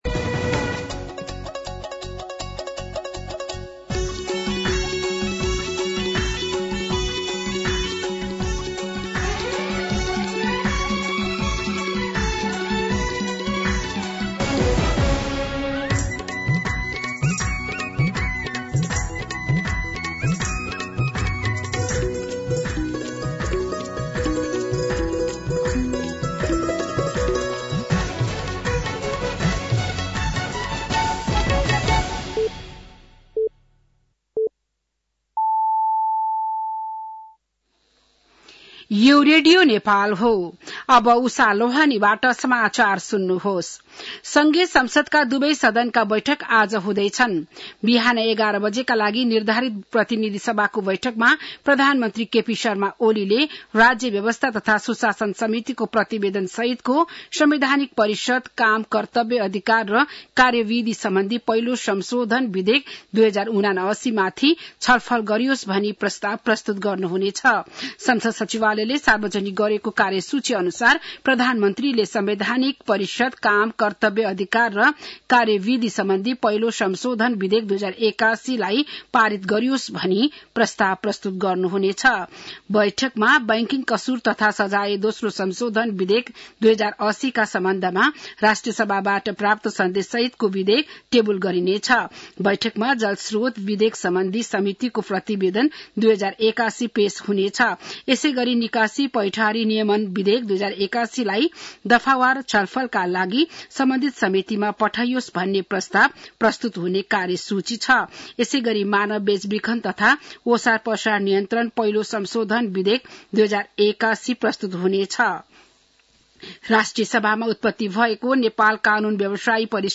बिहान ११ बजेको नेपाली समाचार : १७ चैत , २०८१